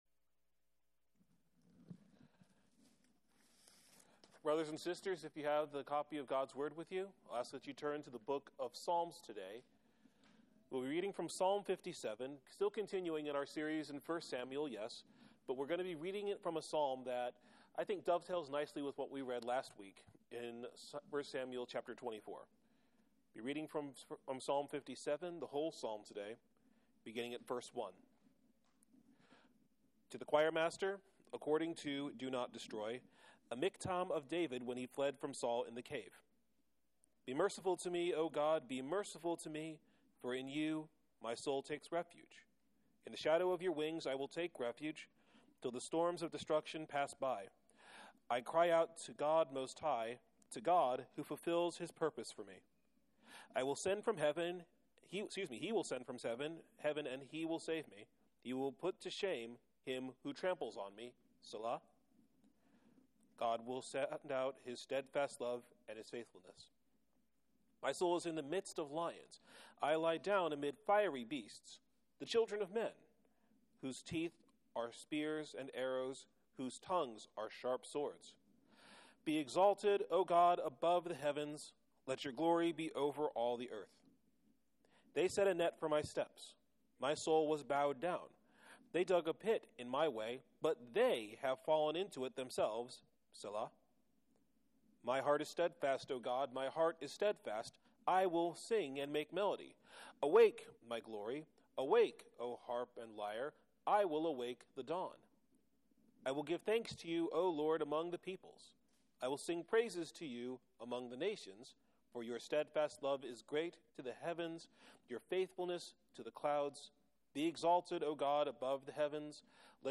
Sermon Text: Psalm 57:1-11 Theme: Because God will surely save all those who turn to Him for help, the distressed believer should have the exaltation of the Lord always on his lips and in his heart.